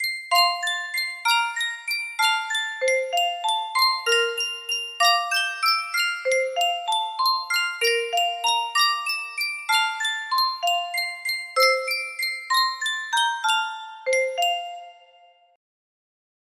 Sankyo Music Box - Bury Me Not on the Lone Prairie TSG music box melody
Full range 60